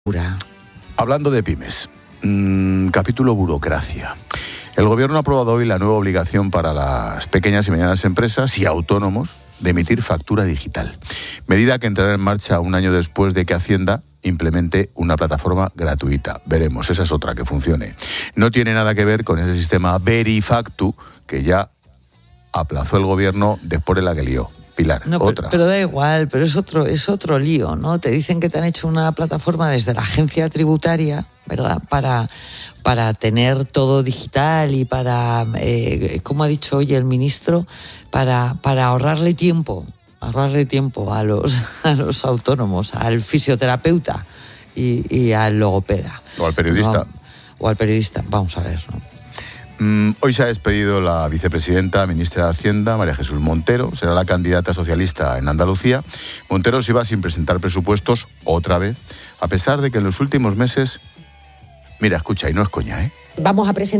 Expósito aprende en Clases de Economía de La Linterna con la experta económica y directora de Mediodía COPE, Pilar García de la Granja, sobre la nueva obligación de factura digital para pymes y autónomos